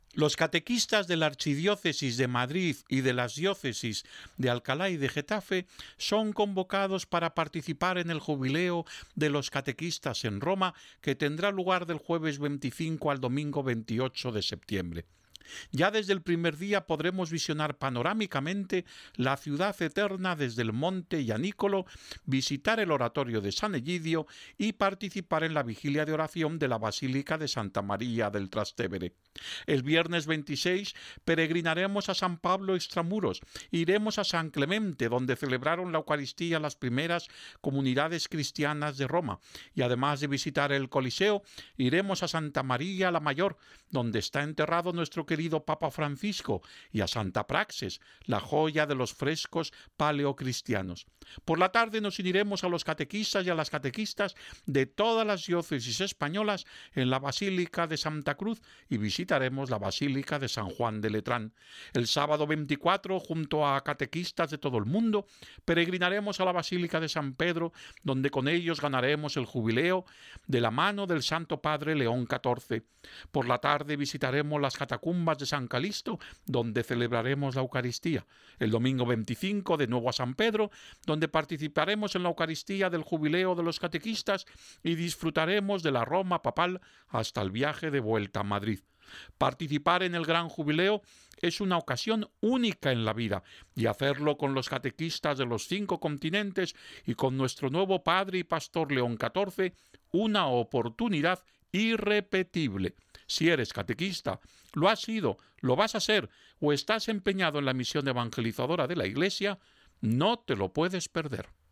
en una entrevista que le hicieron con motivo del Jubileo.